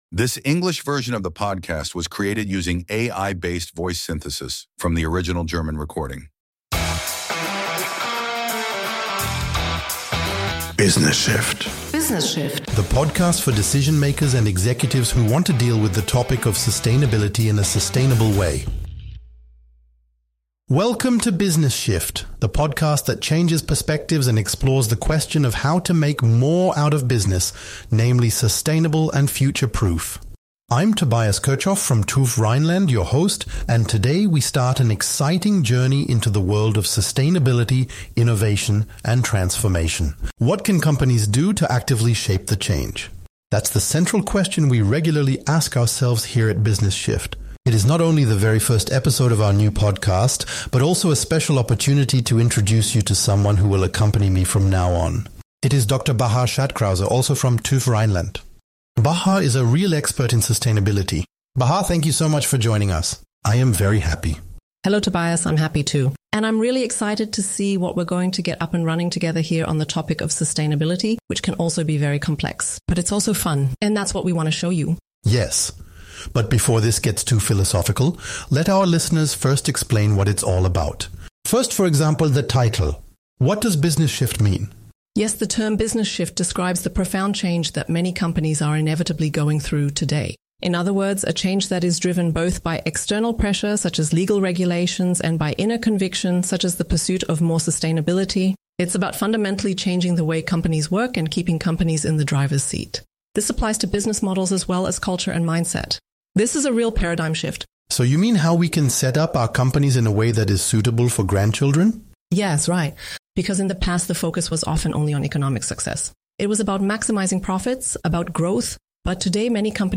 episode were generated using artificial intelligence based on the